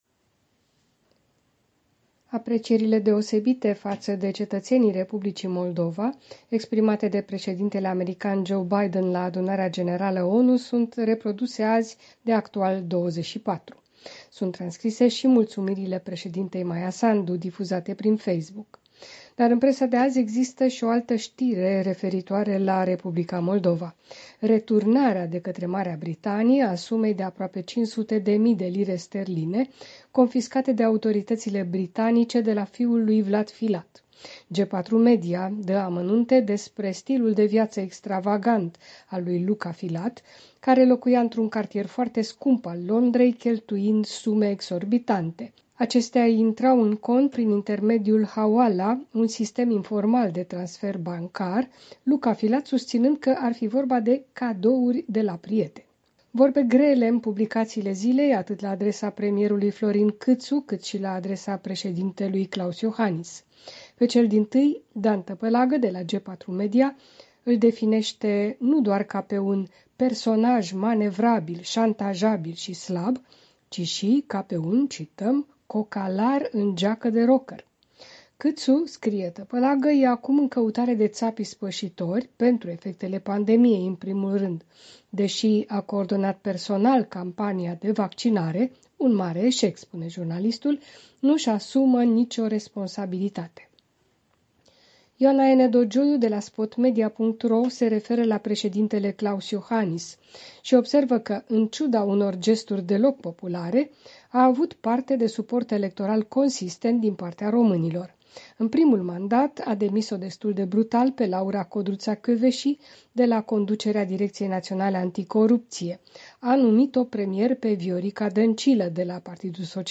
Revista presei de la București.